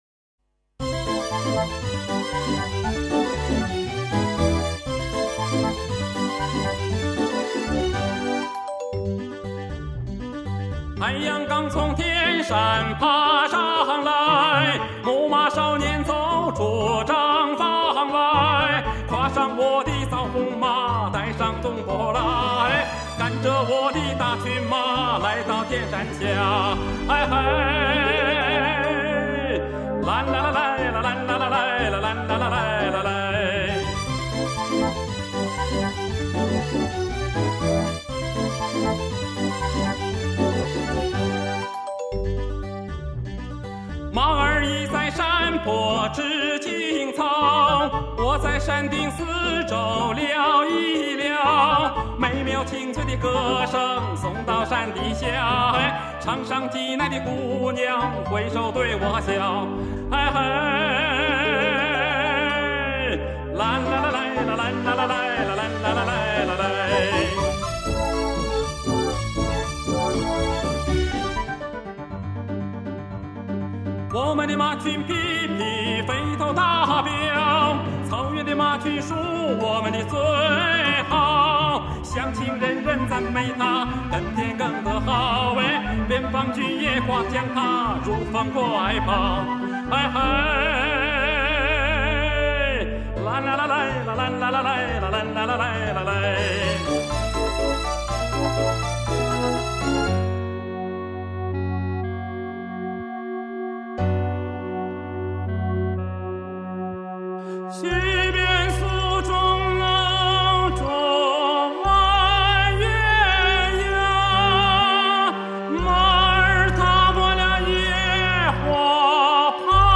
男高音